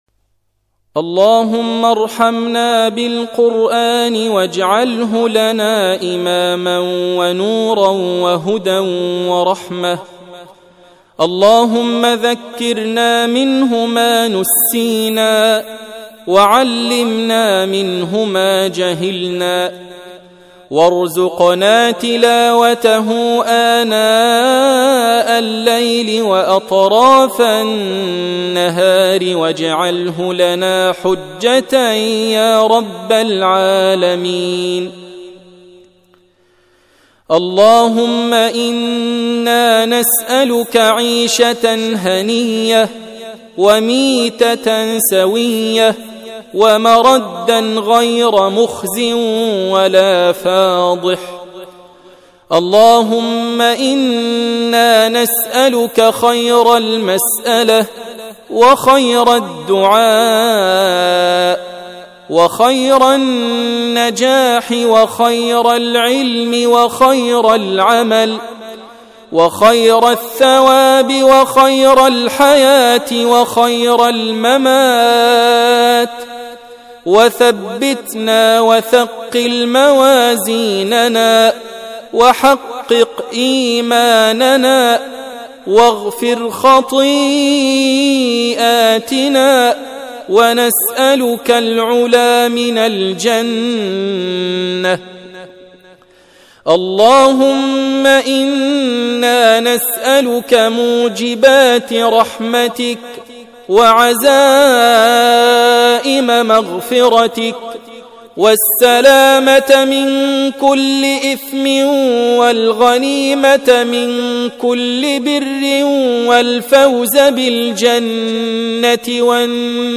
أدعية وأذكار